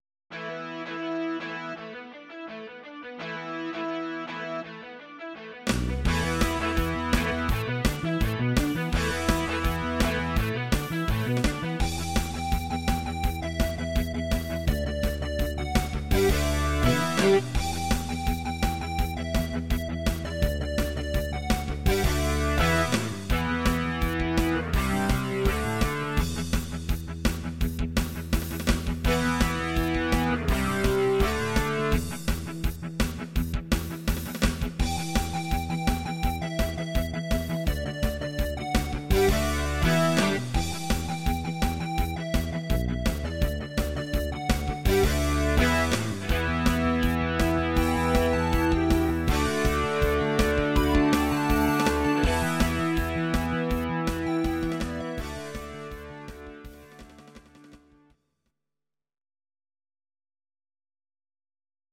Audio Recordings based on Midi-files
Rock, 1970s